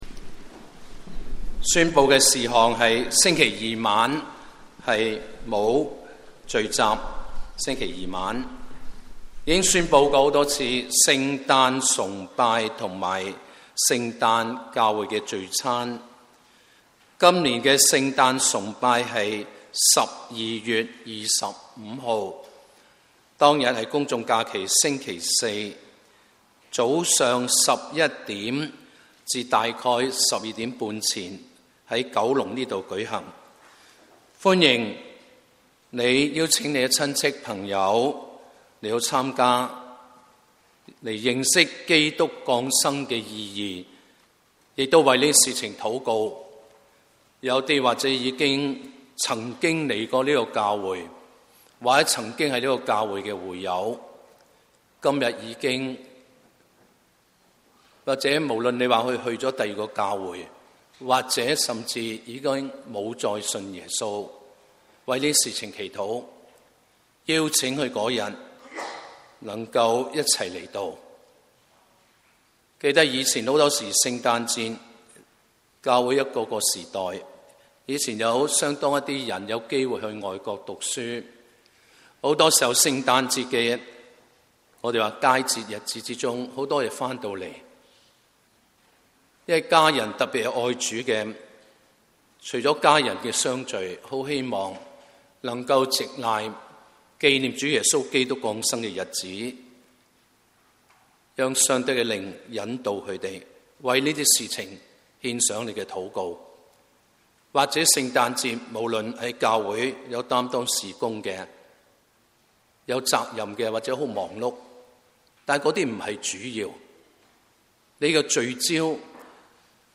Sunday Services – 港九五旬節會